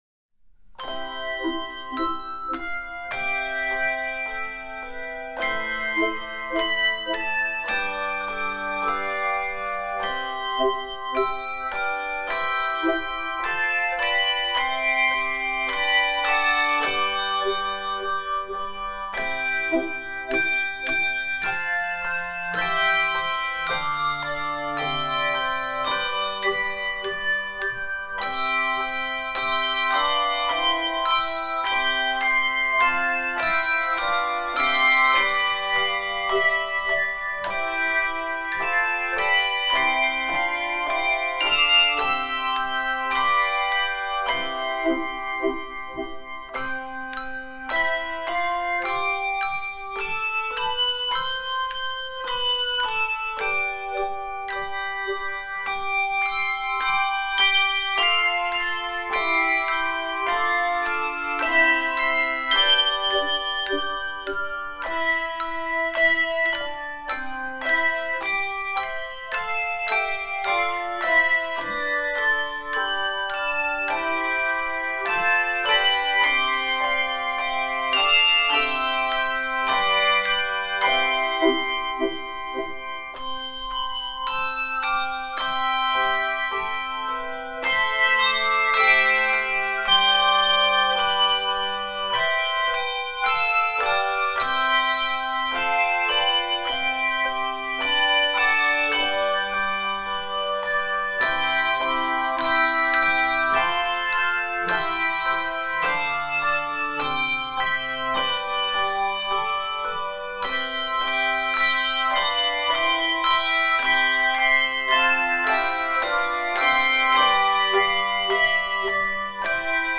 Voicing: Handbells 2-5 Octave